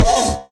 骷髅马：受伤
骷髅马在受伤时随机播放这些音效
Minecraft_skeleton_horse_hurt2.mp3